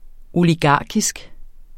Udtale [ oliˈgɑˀkisg ]